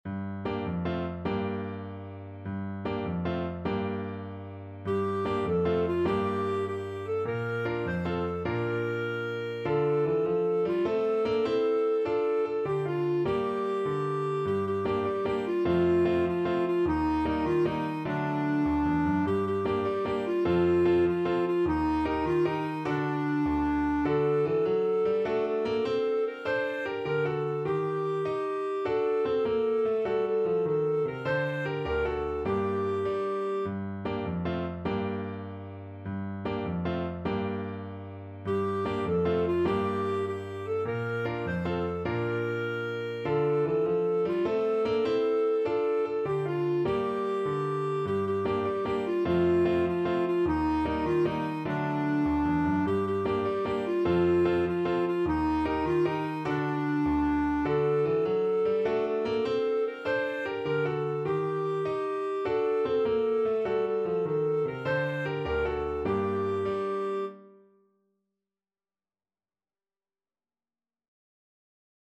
Traditional Music of unknown author.
With energy .=c.100
6/8 (View more 6/8 Music)